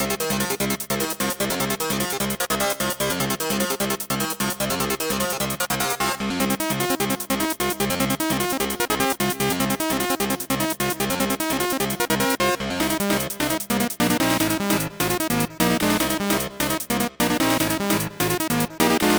A fast paced small piece under work